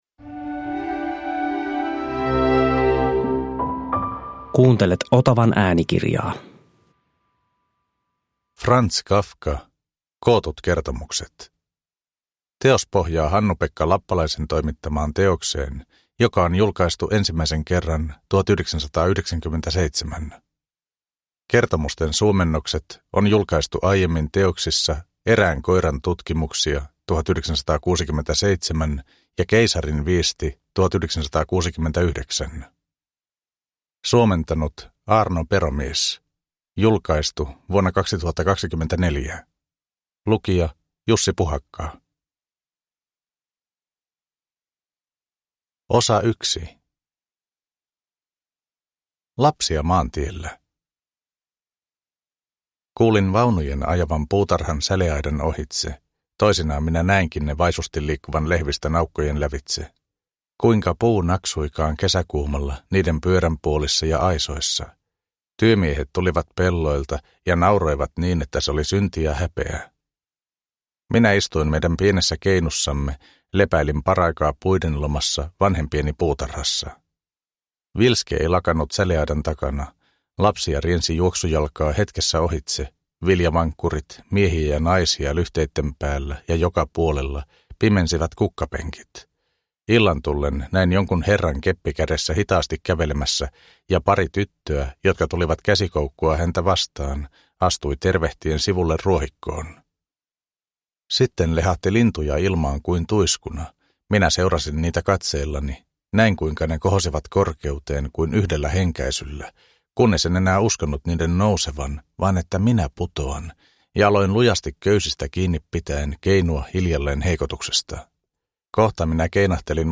Kootut kertomukset – Ljudbok